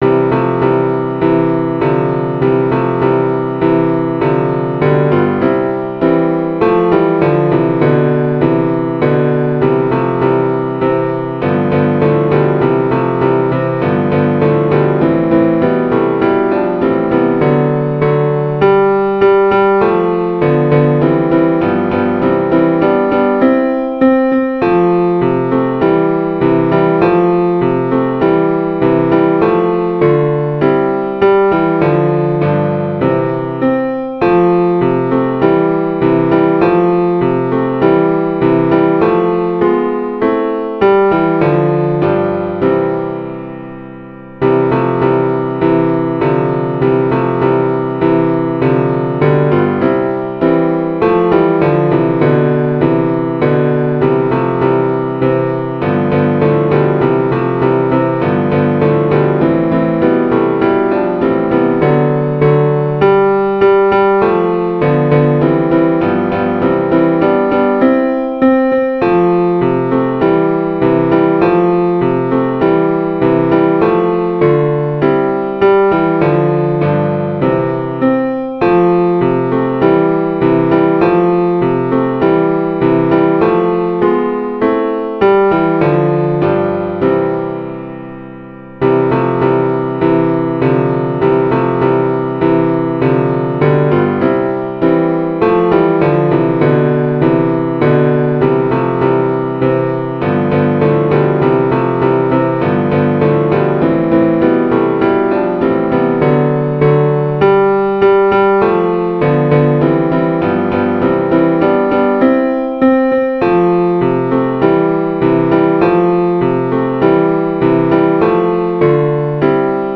par le Chœur du Léman de Coppet
Tutti Ténor 1 Ténor 2 Baryton Basse